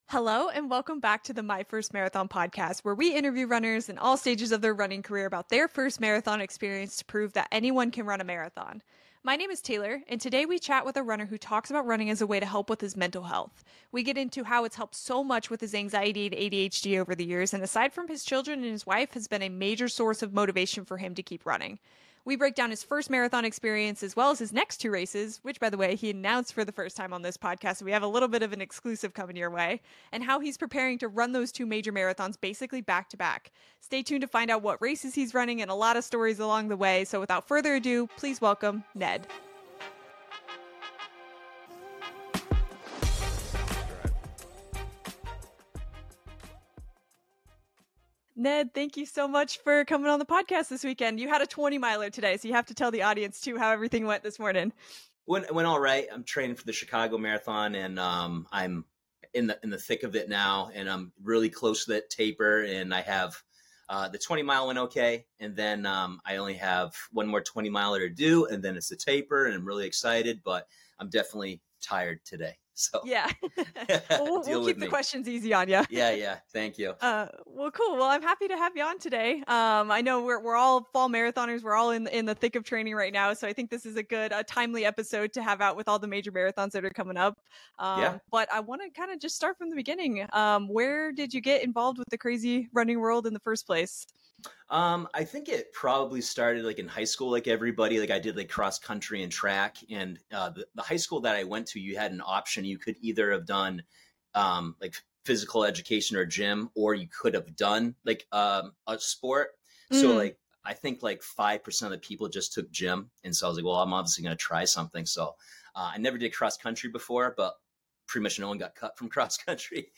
On today's episode, we chat with a runner who talks about running as a way to help with his mental health. We get into how it's helped so much with his anxiety, and how it's been a major source of motivation for him.